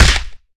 hugeLogHit1.wav